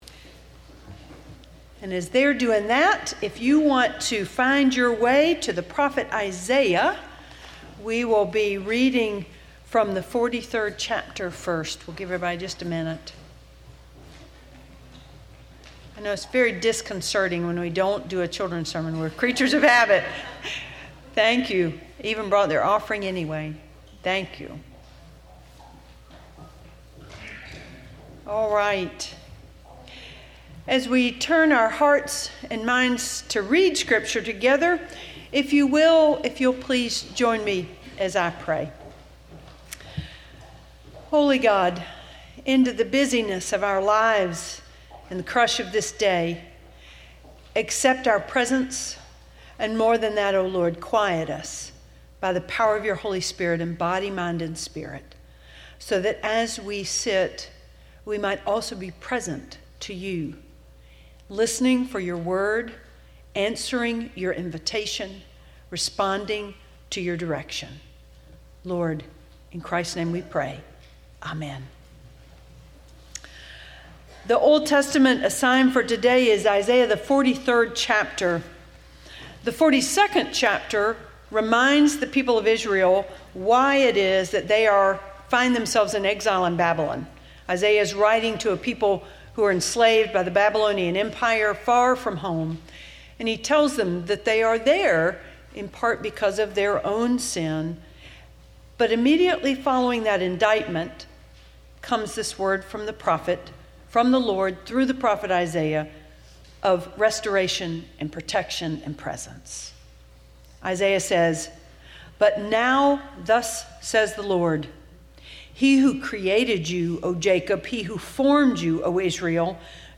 Sermon Archive - Forest Lake Presbyterian Church